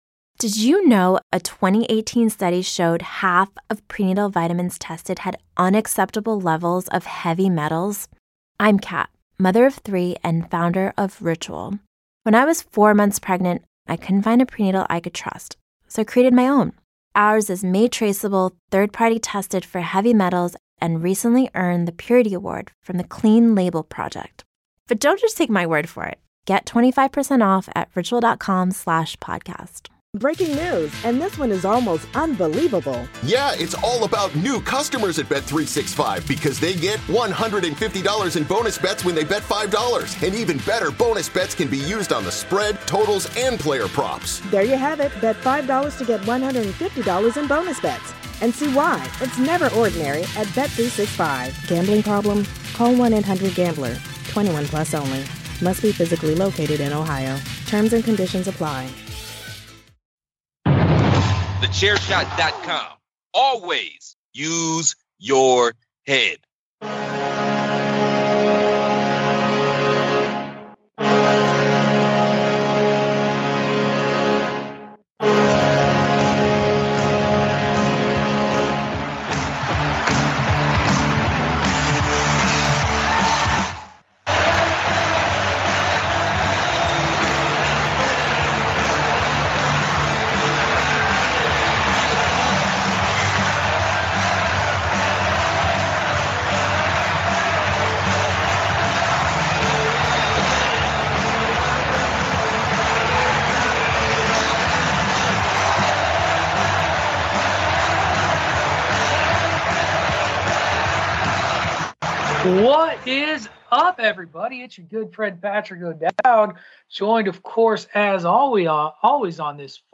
The interview is recorded in Dowding’s former office in Bentley Priory, Stanmore, which was Headquarters Fi…